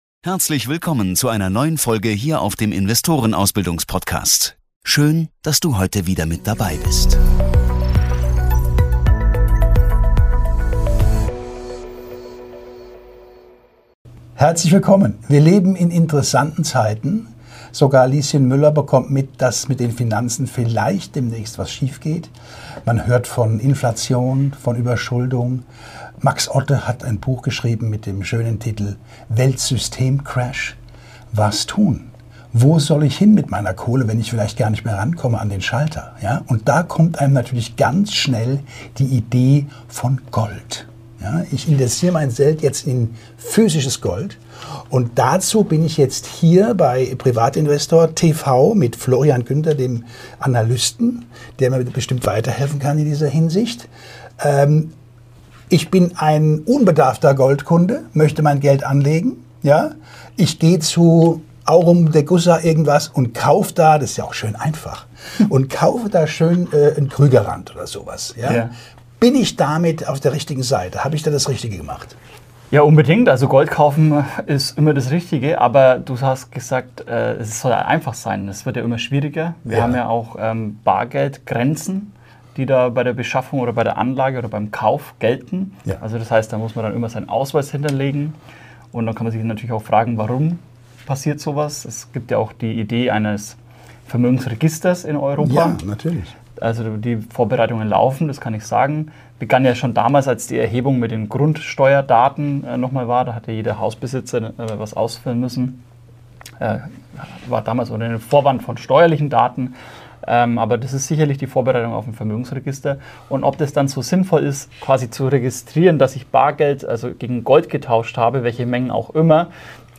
Ein informatives Interview über die Möglichkeiten der physischen Goldanlage außerhalb der EU und deren Vor- und Nachteile gegenüber klassischen Anlageformen.